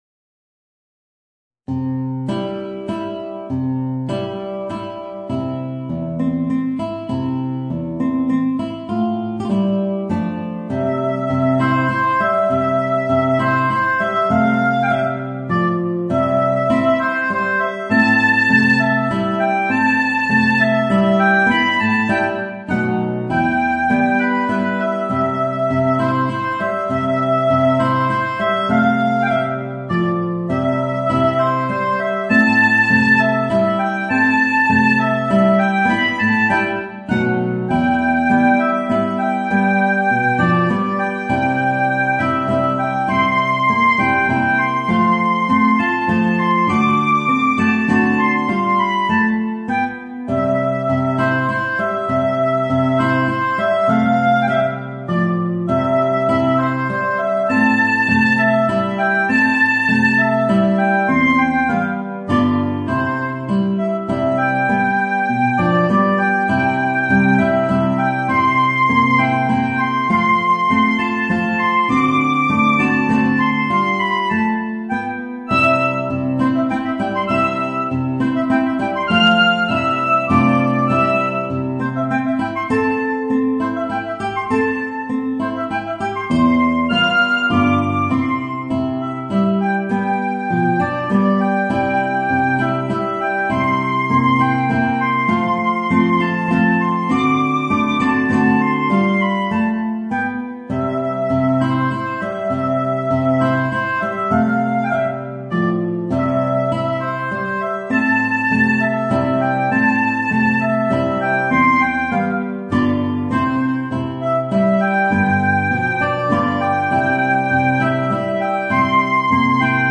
Voicing: Guitar and Oboe